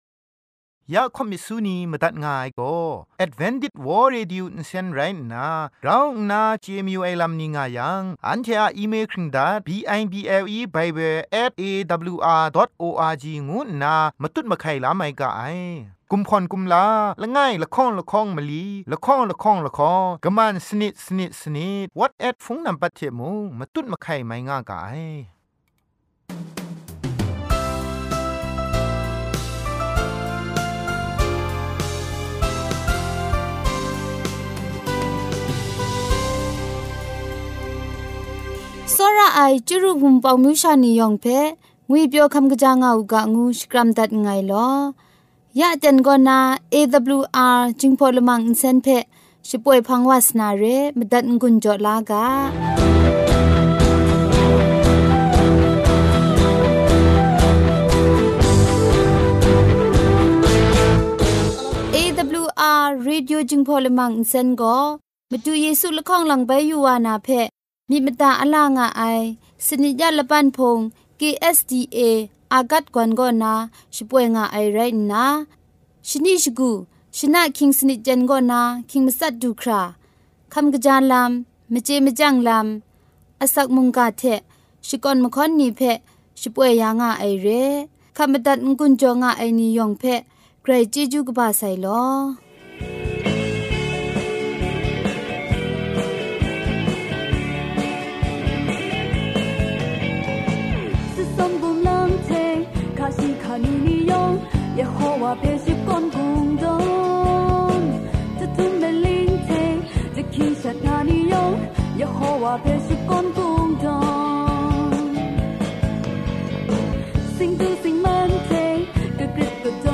Gosple song,health talk,sermon.